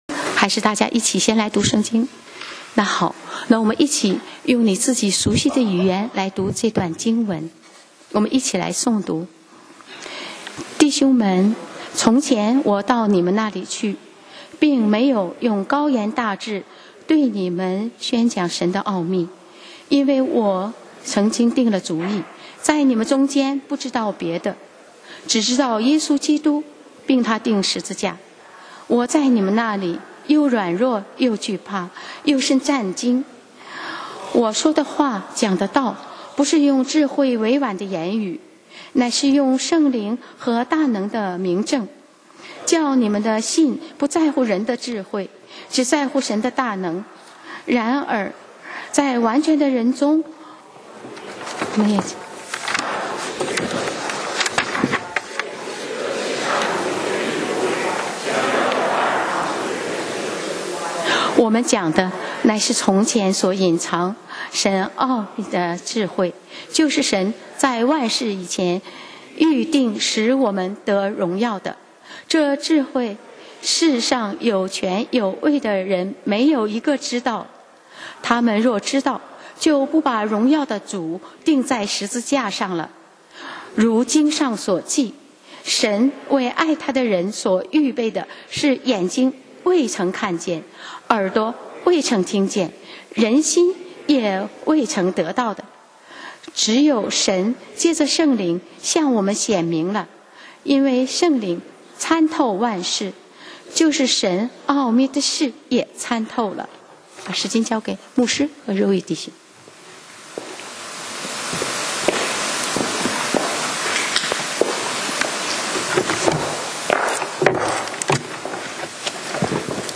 講道 Sermon 題目 Topic：软弱与得胜系列二：从愚昧到智慧 經文 Verses：林前2：1-10. 1弟兄們、從前我到你們那裡去、並沒有用高言大智對你們宣傳 神的奧秘。